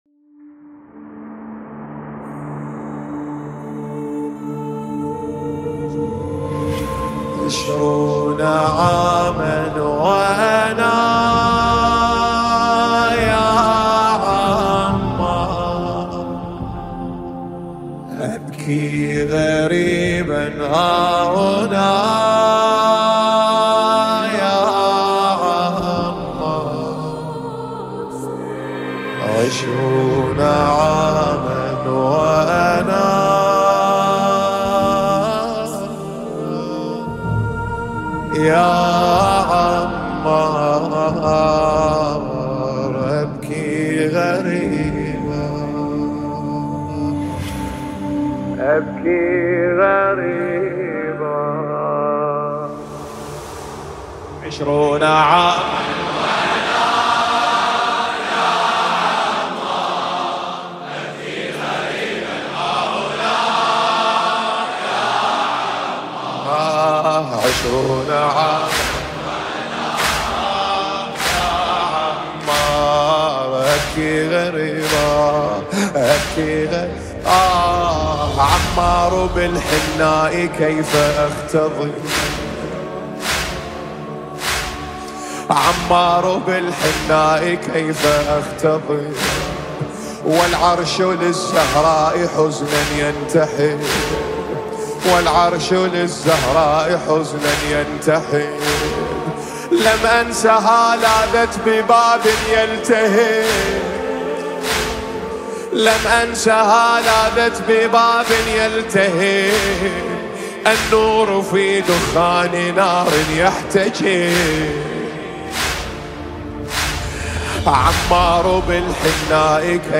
مداحی عربی دلنشین